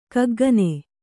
♪ kaggane